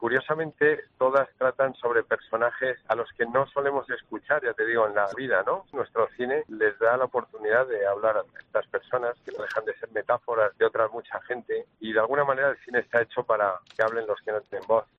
COPE entrevista al vicepresidente de la Academia de las Artes y las Ciencias Cinematográficas a pocas horas de la Gala de los 32 premios Goya